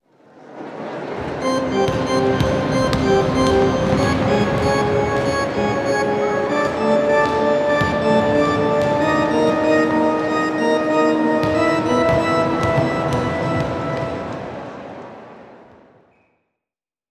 Tiempo muerto en un partido de baloncesto 01
melodía
Sonidos: Música
Sonidos: Acciones humanas
Sonidos: Deportes